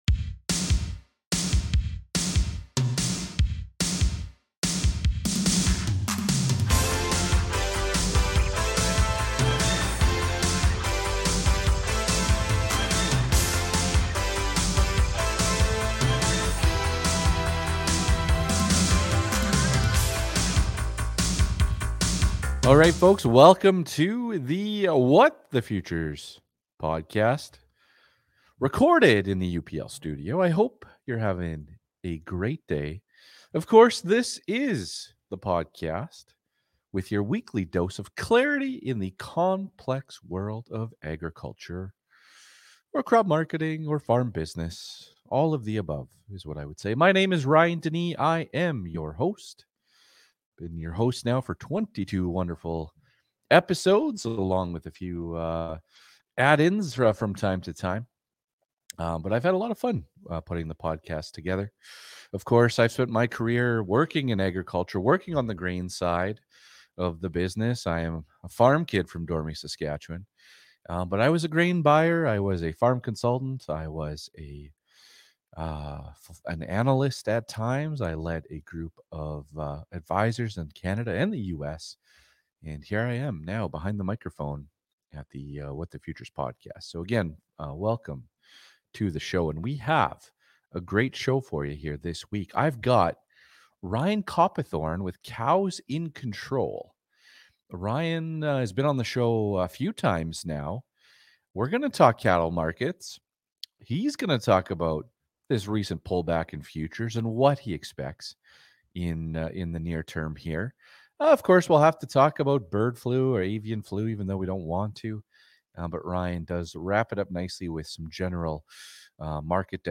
Episode #22 was recorded in the UPL Studio!